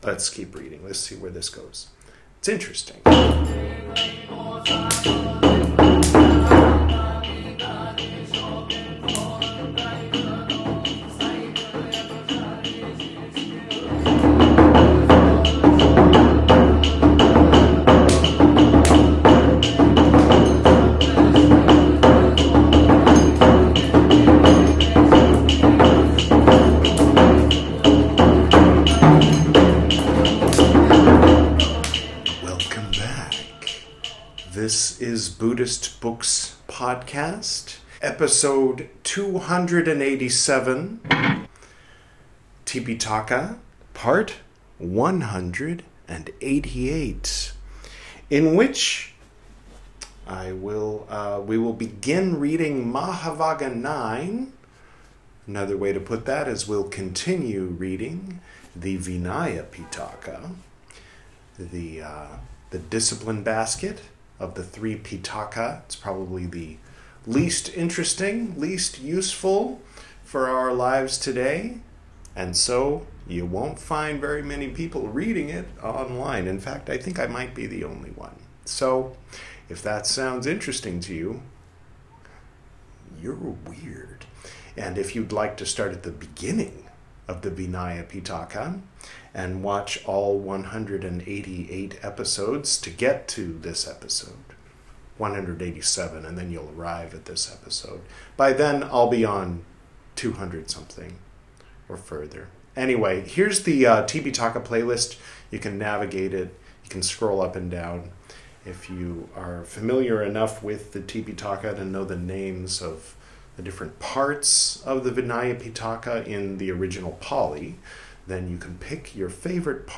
This is Part 188 of my recital of the 'Tipiṭaka,' the 'Three Baskets' of pre-sectarian Buddhism, as translated into English from the original Pali Language. In this episode, we'll begin reading 'Mahāvagga IX,' from the 'Vinaya Piṭaka,' the first of the three 'Piṭaka,' or 'Baskets.'